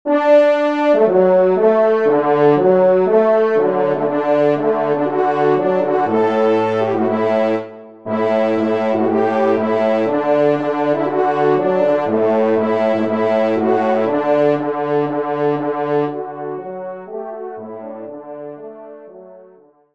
Genre :  Musique Religieuse pour Trois Trompes ou Cors
Pupitre 3° Trompe